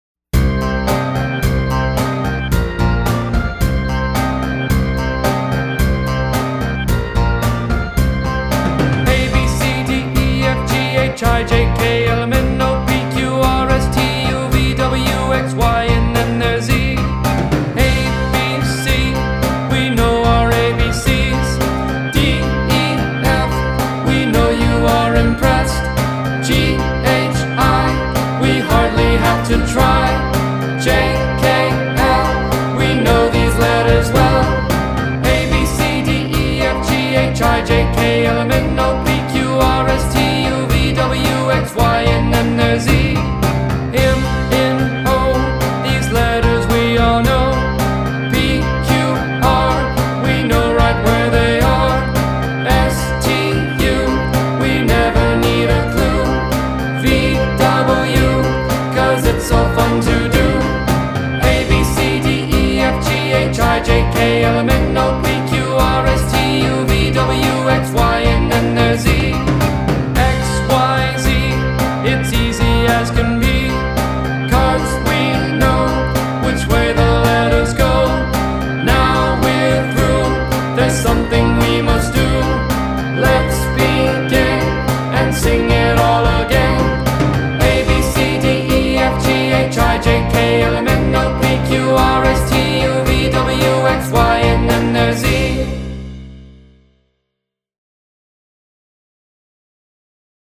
Children’s Songs